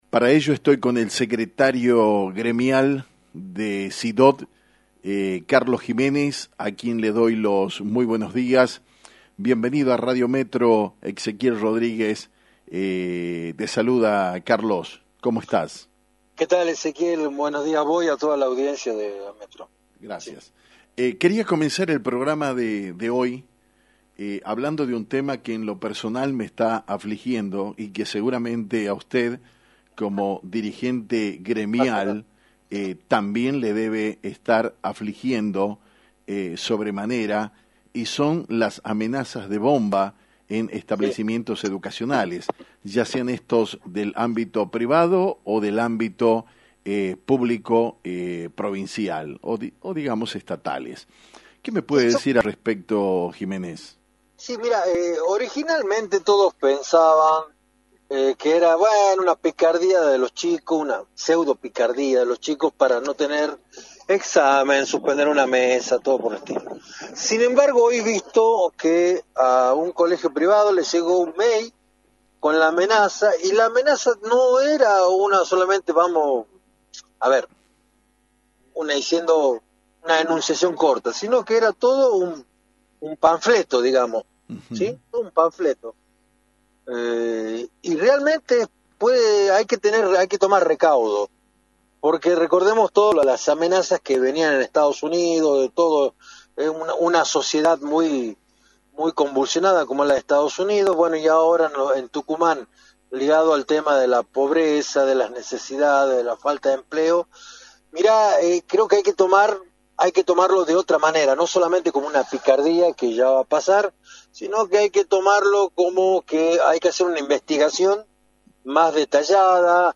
pasó por Metro 89.1 mhz